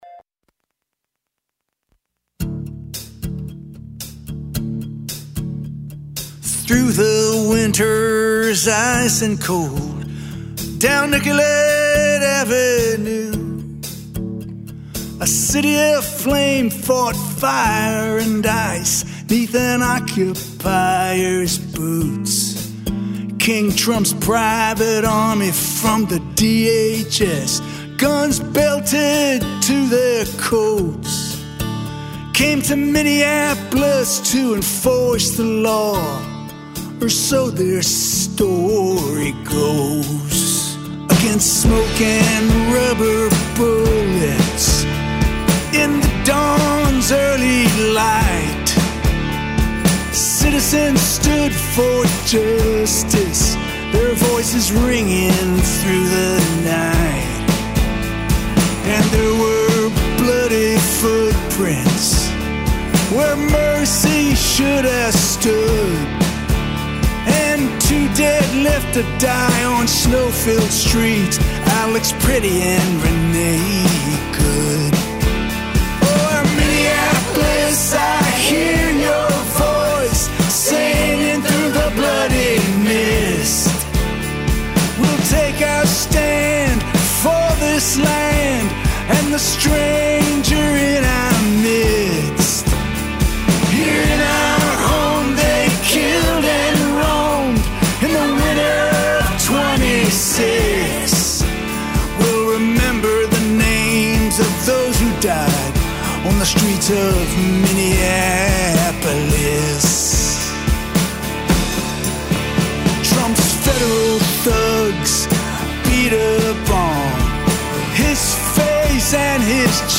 Letture